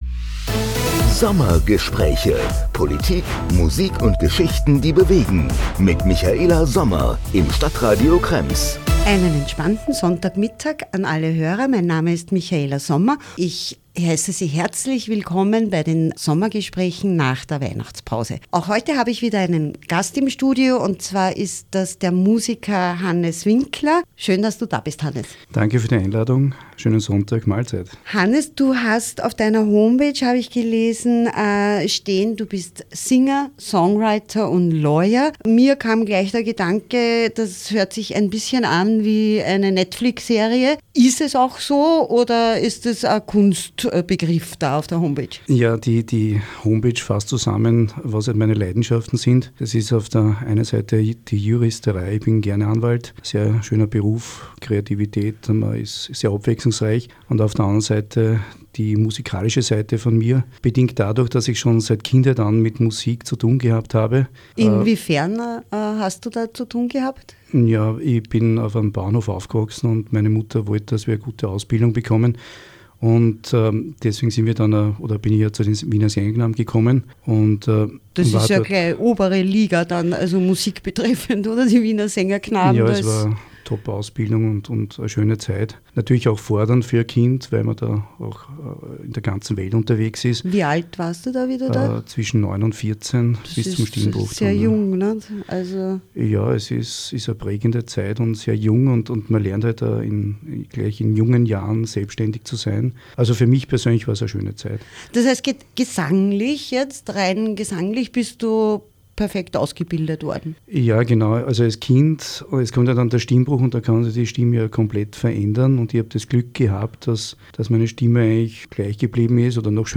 Im Gespräch gibt er spannende Einblicke in seinen außergewöhnlichen Lebensweg, verbindet scheinbar unterschiedliche Welten und erzählt, wie er seine Leidenschaften in Einklang bringt.